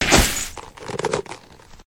PixelPerfectionCE/assets/minecraft/sounds/mob/horse/leather.ogg at mc116
leather.ogg